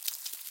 Minecraft Version Minecraft Version 1.21.5 Latest Release | Latest Snapshot 1.21.5 / assets / minecraft / sounds / mob / silverfish / step1.ogg Compare With Compare With Latest Release | Latest Snapshot